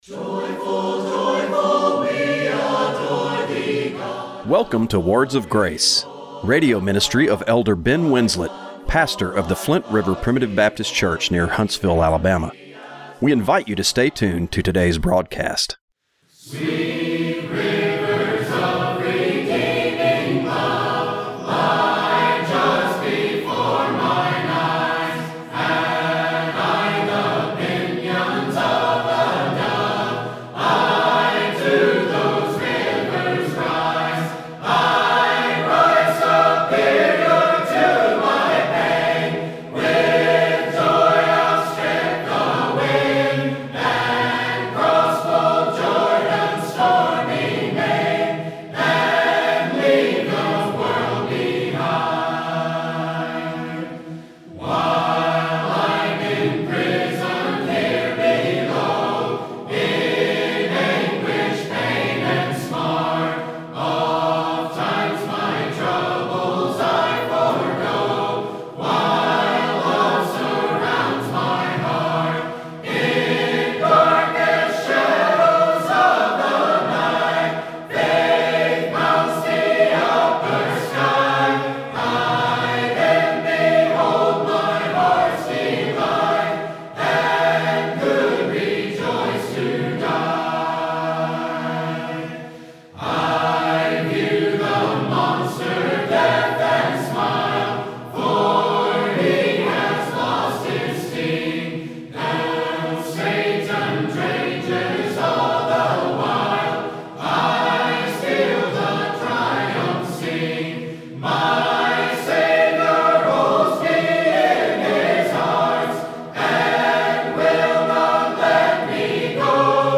Radio broadcast for March 15, 2026.